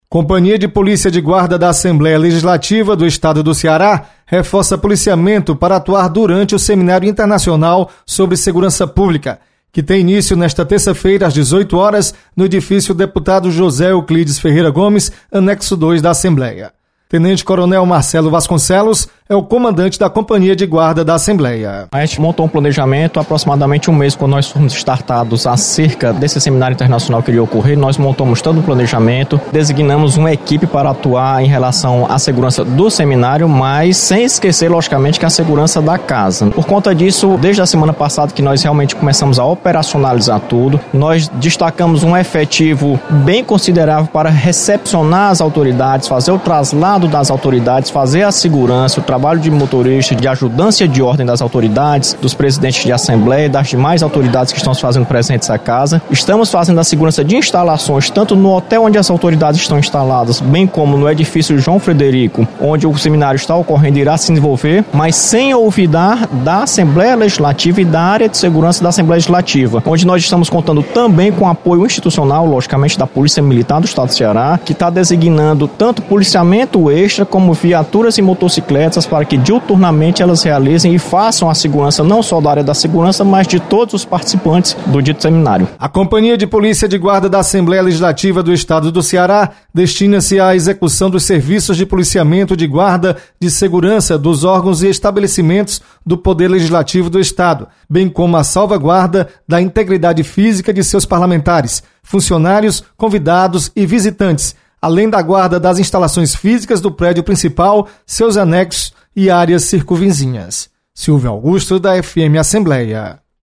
Montado esquema de segurança na Assembleia Legislativa para Seminário Internacional sobre Segurança Pública. Repórter